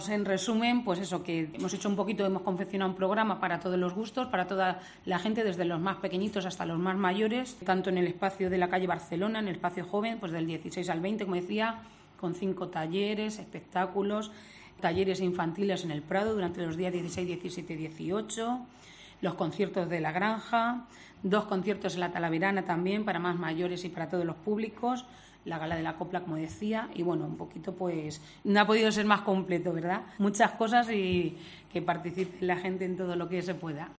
Concejala de Festejos, Fátima de la Flor